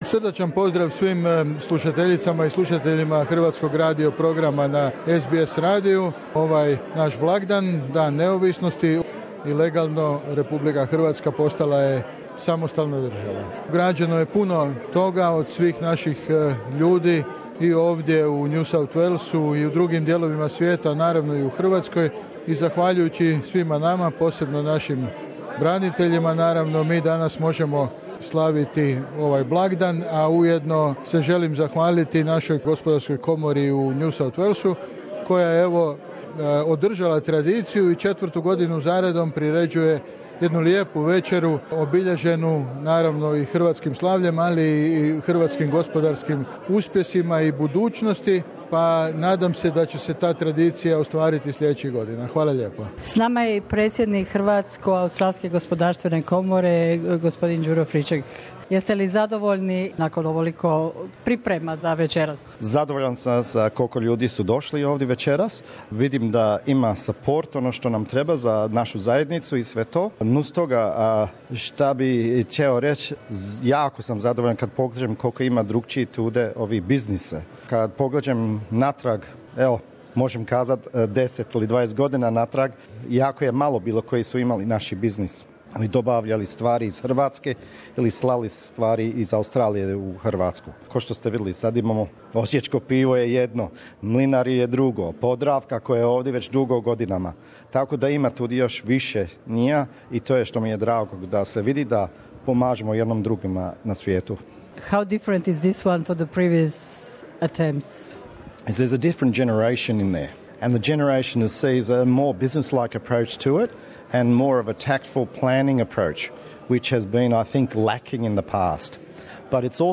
Komentari gostiju sa svečanosti obilježavanja Dana neovinosti Republike Hrvatske, u Sydneyu koju je organizirala Australsko Hrvatska Gospodarska komora ACC u NSW: